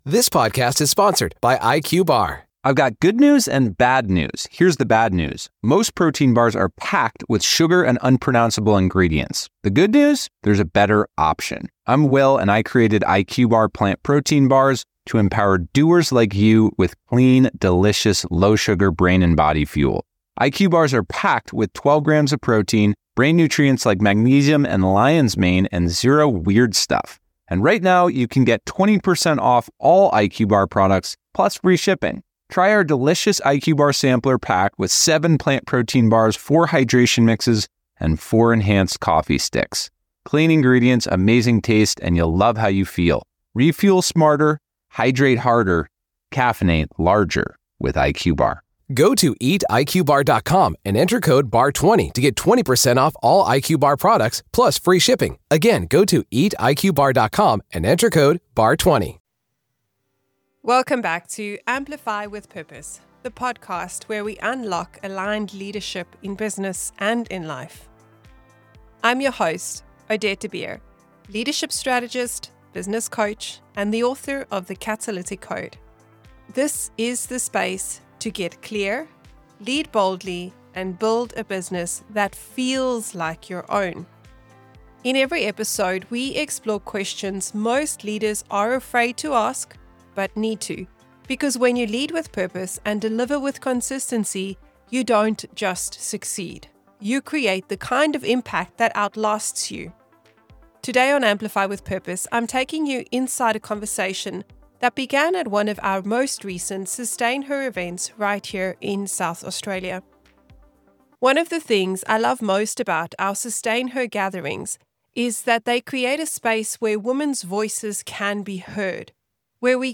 ✨ Amplify With Purpose is recorded live from our SustainHer Hills events—a comm